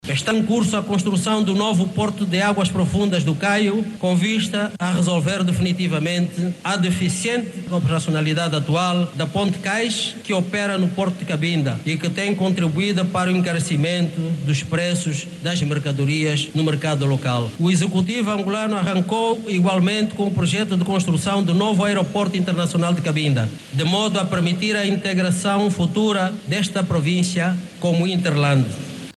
Francisco Furtado destaca construção do Aeroporto Internacional de Cabinda no acto central no dia que se celebra o início da luta armada em Angola